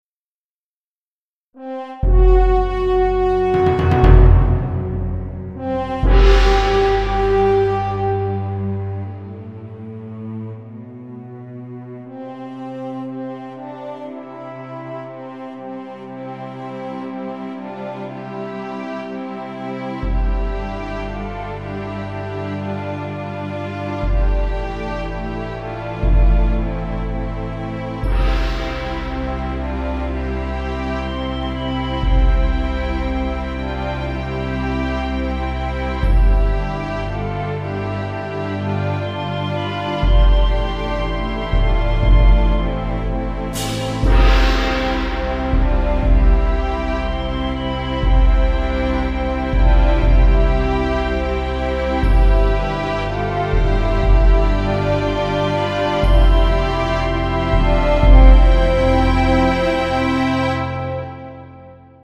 Classica [instrumental]